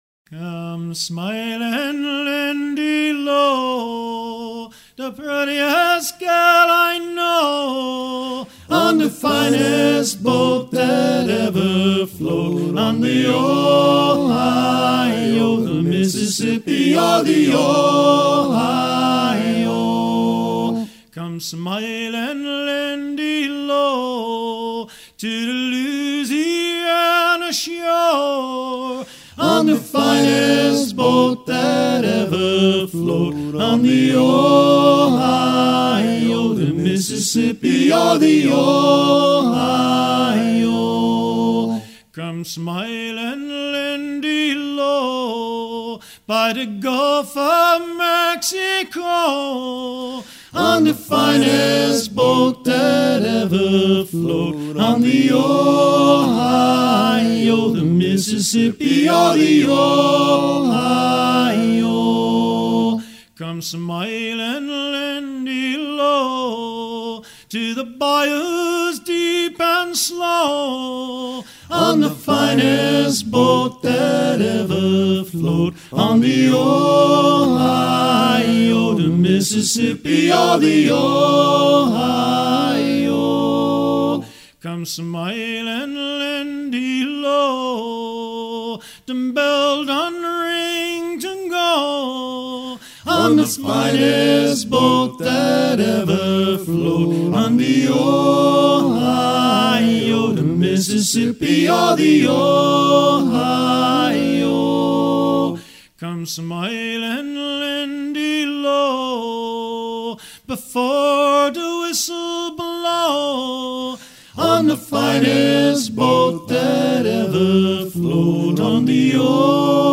à hisser main sur main
Pièce musicale éditée